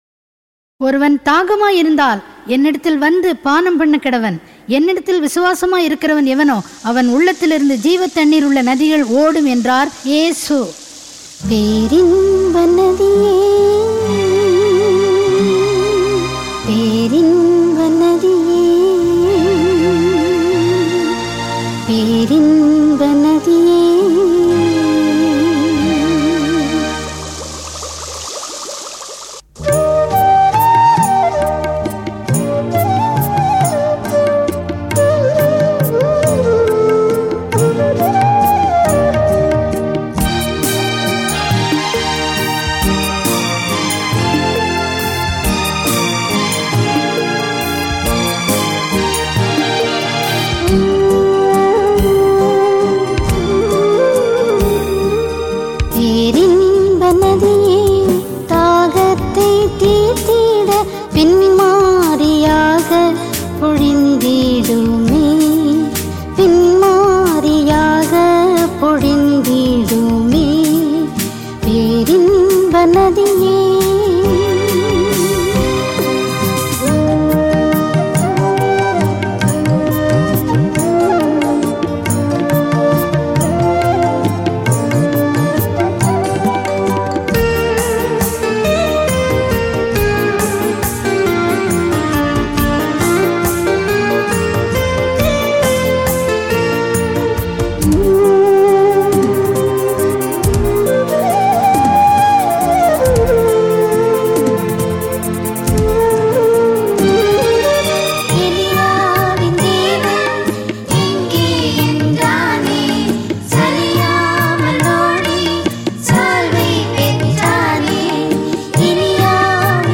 Royalty free Christian music.